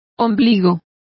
Complete with pronunciation of the translation of navels.